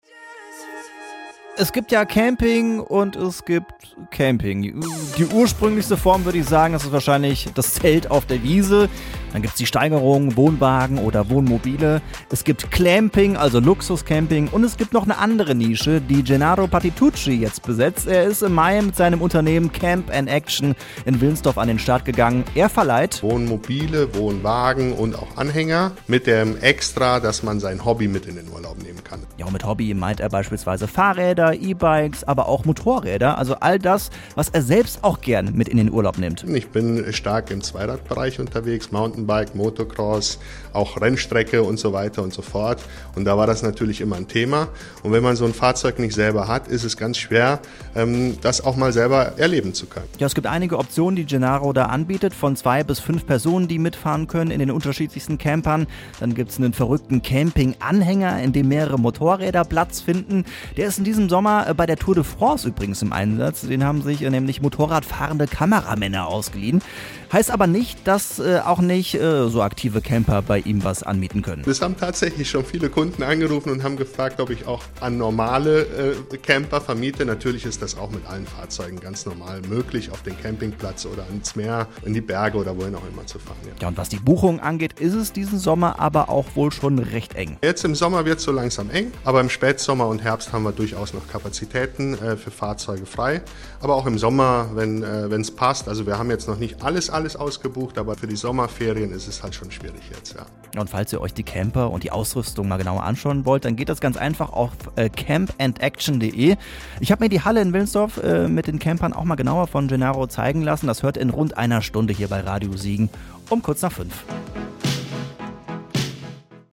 Wir haben mit ihm darüber gesprochen, wie er sein Hobby zum Geschäft gemacht hat: